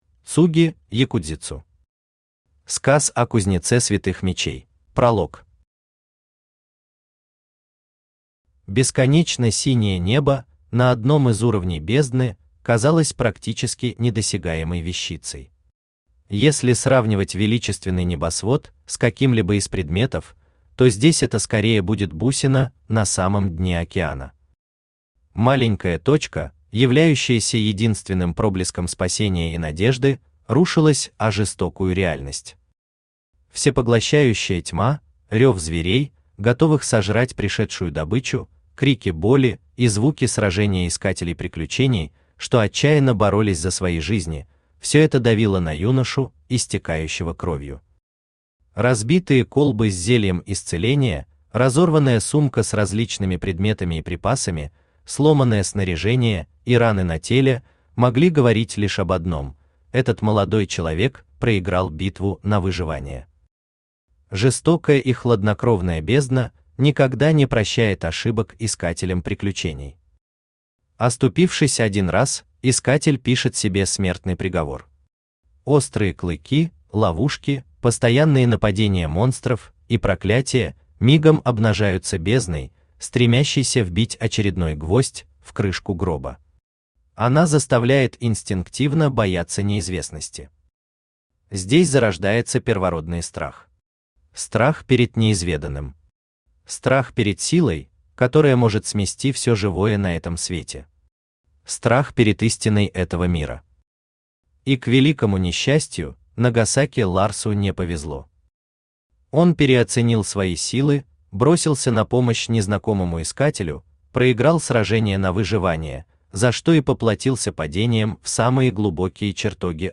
Aудиокнига Сказ о кузнеце святых мечей Автор Цуги Ёкудзицу Читает аудиокнигу Авточтец ЛитРес.